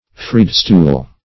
Freedstool \Freed"stool`\, n. [Obs.]